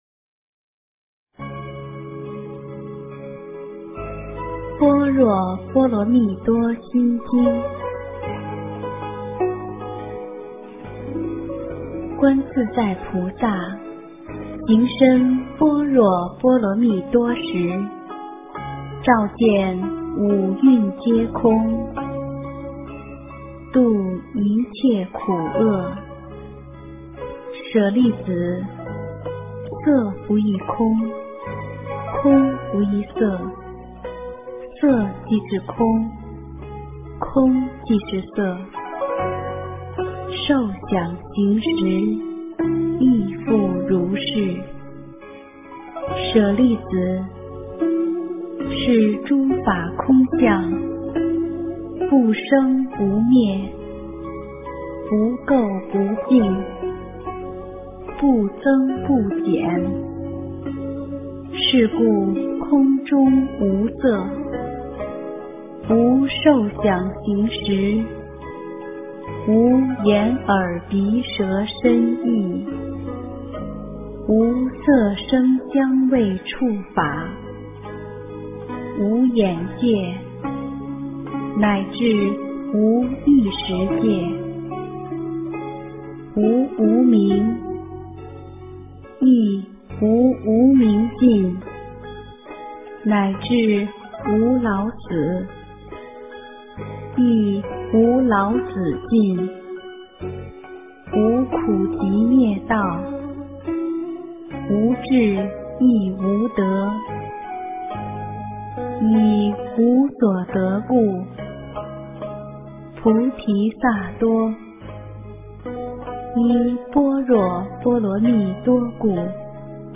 心经-恭诵 诵经 心经-恭诵--佚名 点我： 标签: 佛音 诵经 佛教音乐 返回列表 上一篇： 心经 下一篇： 心经 相关文章 和心曲--瑜伽静心曲 和心曲--瑜伽静心曲...